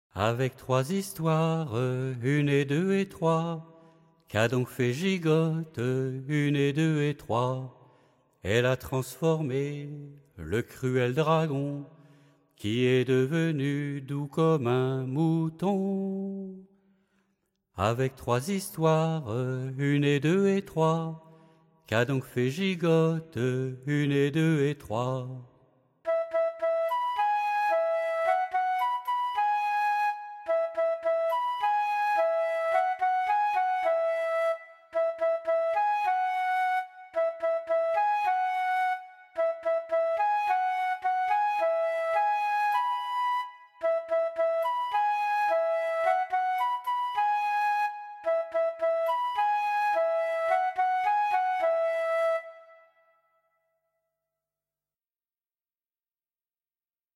École Élémentaire Louis Pergaud de Orchamps Vennes une classe qui chante
Solidarité version instrumentale